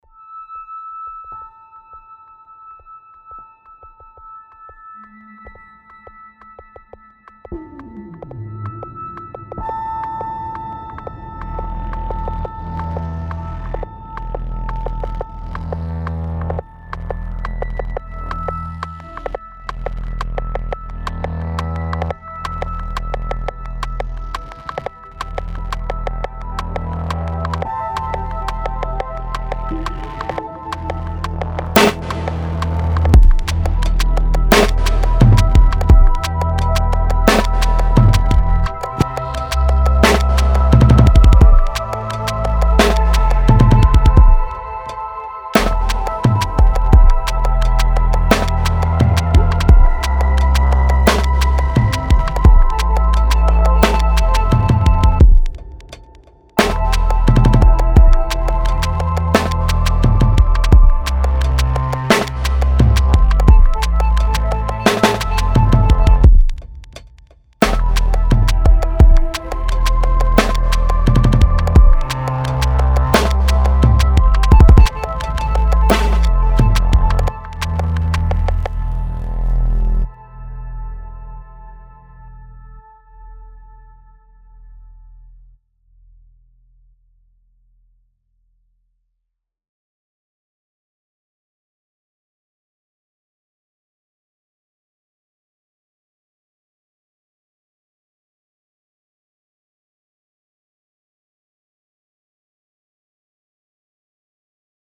Our free drone and ambiance generator.